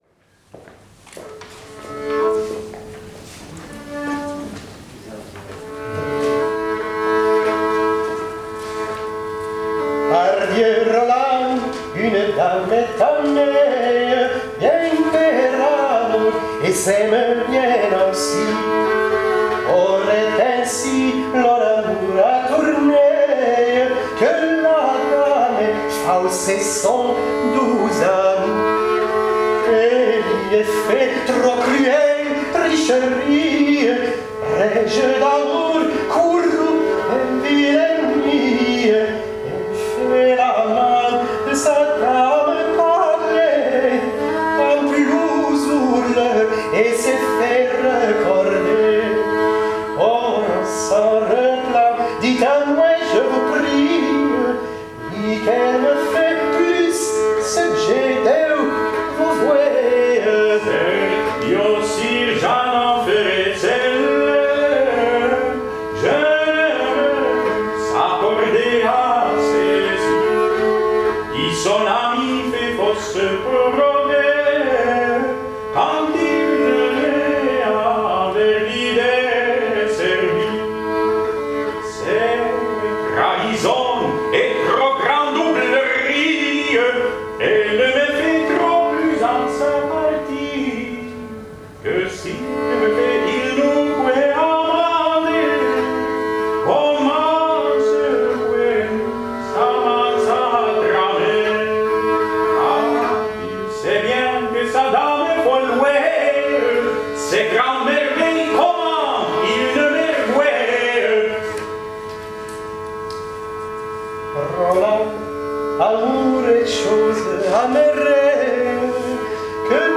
A second post from the performance workshop with graindelavoix, sponsored by the Leverhulme Trust and held at St Hugh’s College, Oxford in March 2017.